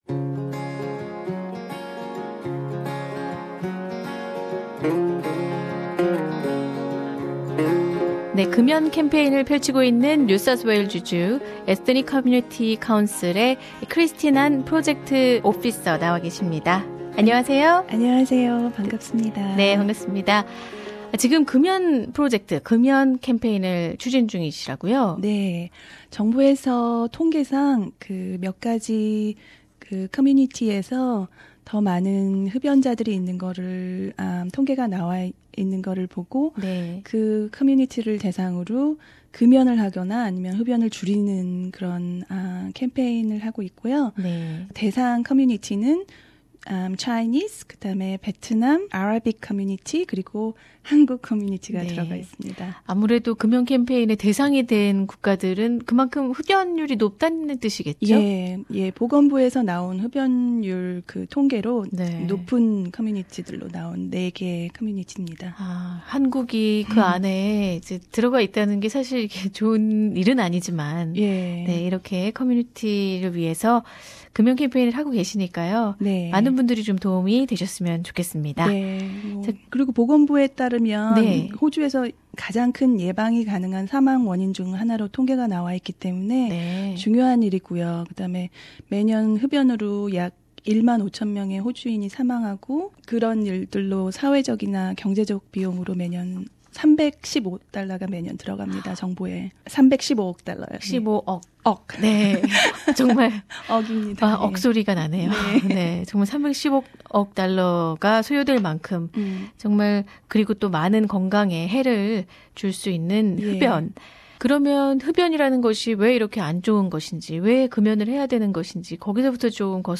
[Interview] Why Should I Quit smoking?